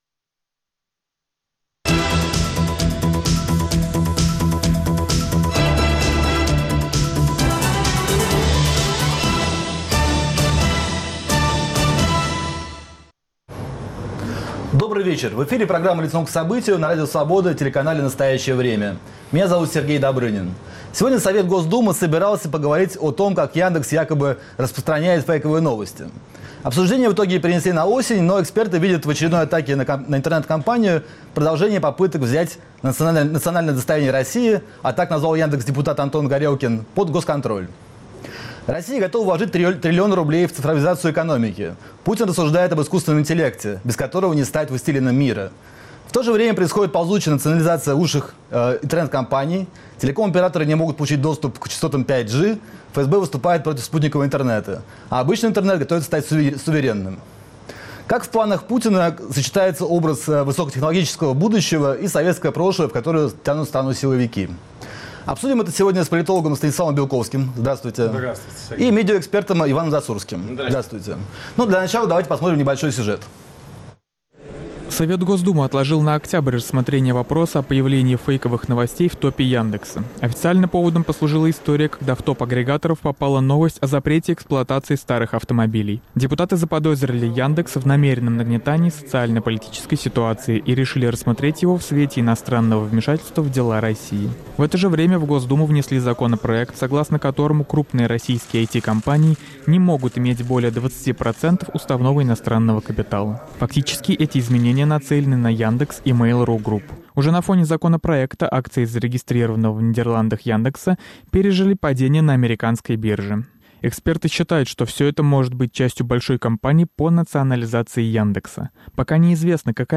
Как в планах Путина сочетаются образ высокотехнологического будущего и советское прошлое, в которое тянут страну силовики? Обсудят политолог Станислав Белковский, медиаэксперт Иван Засурский и писатель Дмитрий Глуховский.